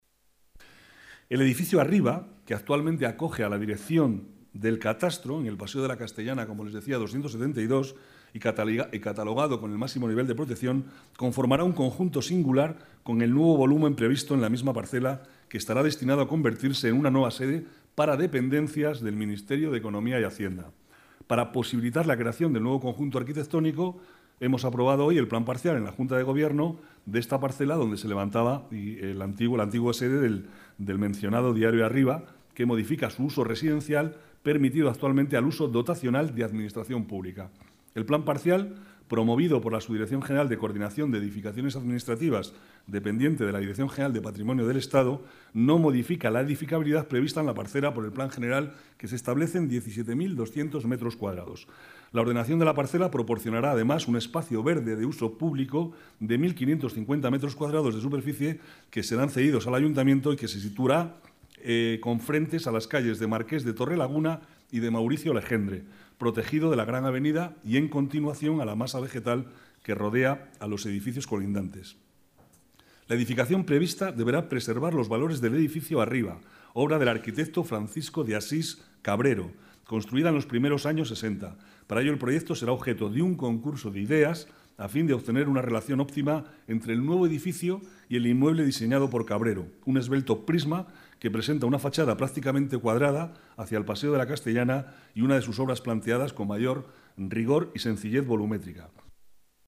Nueva ventana:Declaraciones vicealcalde, Manuel Cobo: proyecto edificio Arriba